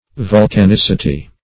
Vulcanicity \Vul`can*ic"i*ty\, n.
vulcanicity.mp3